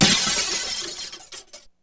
Glass
Glass.wav